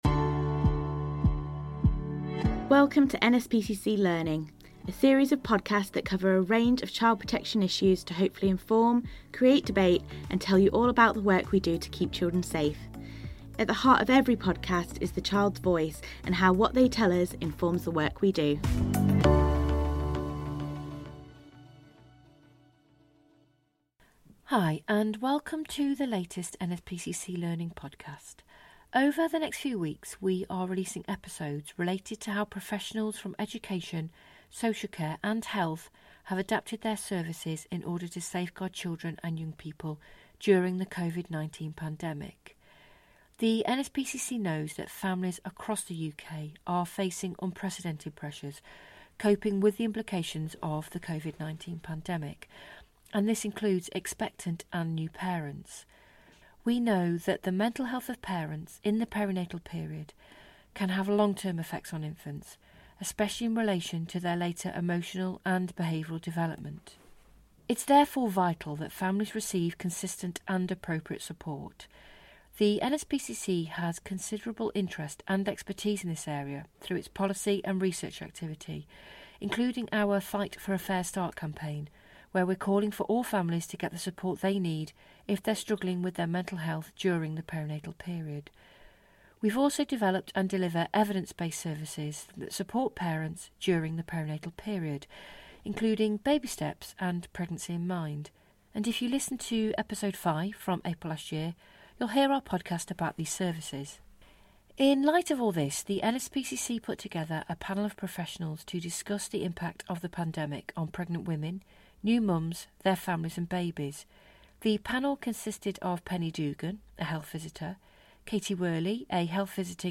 First up in our coronavirus series, we are joined by a midwife, health visitors and perinatal psychiatry experts for a special episode to mark Infant Mental Health Awareness Week (7-12 June 2020). The panel discuss how the pandemic and lockdown is affecting pregnant women, parents, their families and babies; antenatal and postnatal care services have adapted in response; health visitors have been conducting assessments; and how vulnerable families are being supported.